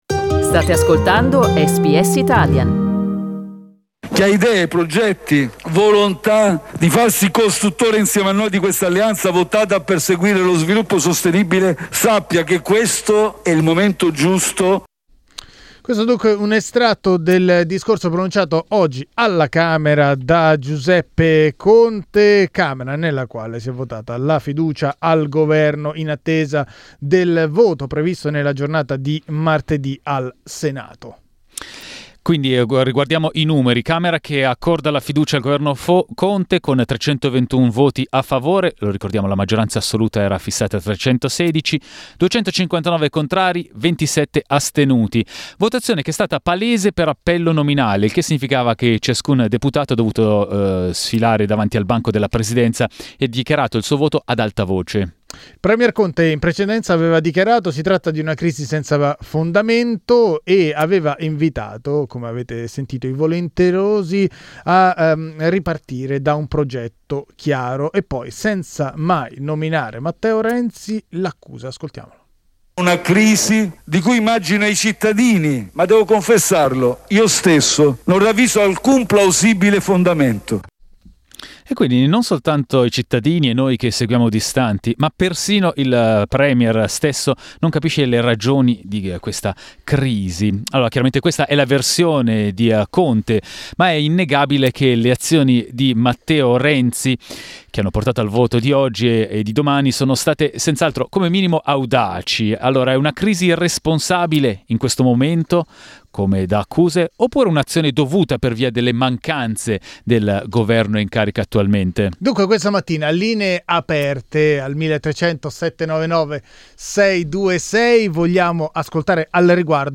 Ne parliamo su SBS Italian con il senatore del PD Francesco Giacobbe e gli ascoltatori.